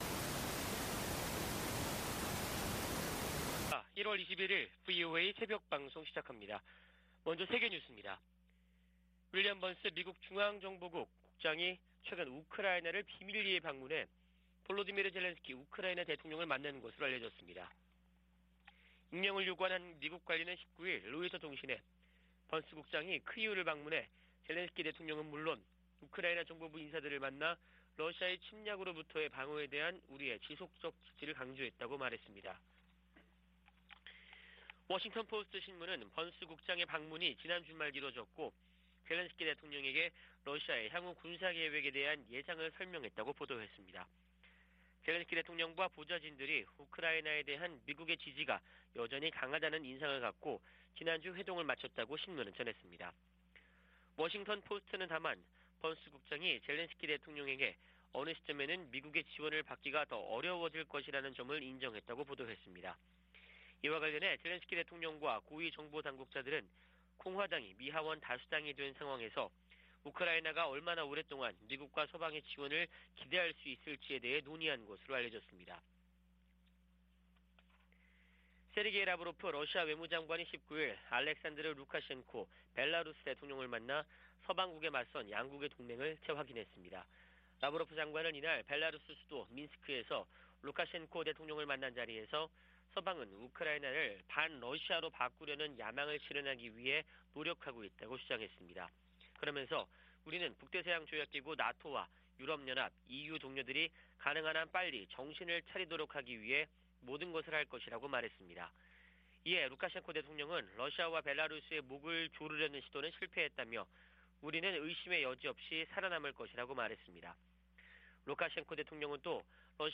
VOA 한국어 '출발 뉴스 쇼', 2023년 1월 21일 방송입니다.